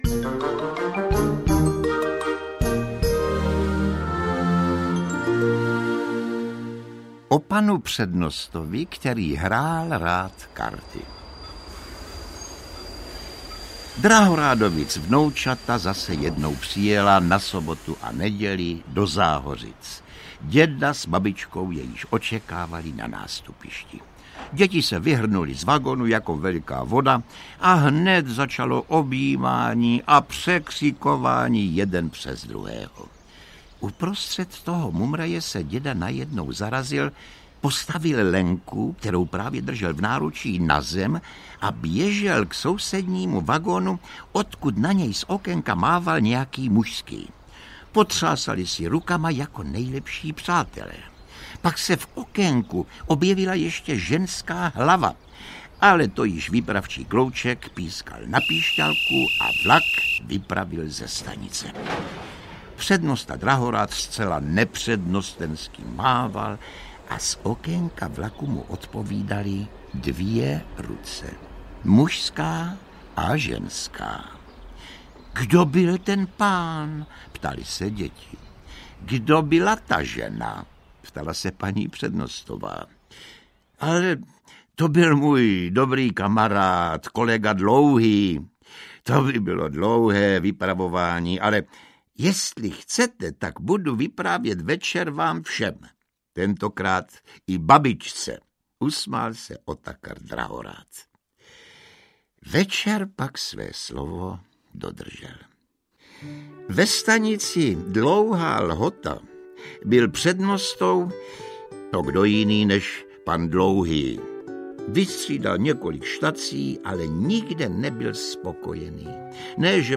Pohádky přednosty Drahoráda audiokniha
Ukázka z knihy